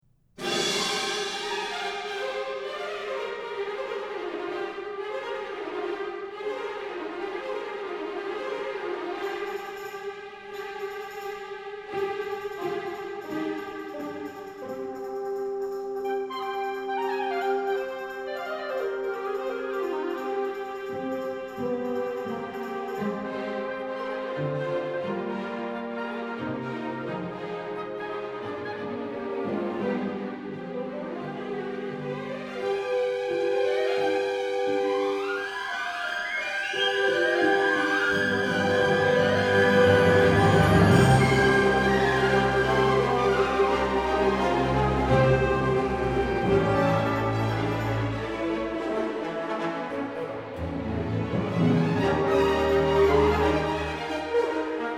★ 俄羅斯近代重要作曲家，曾教出普羅高菲夫、哈察都量等著名學生，交響曲與芭蕾舞曲都有俄羅斯風格旋律與多采多姿的音樂呈現。
為了聲音效果，樂團還坐得比一般狀況下更寬更開闊些。
Allegro vivace (11:32)audio_joy.JPG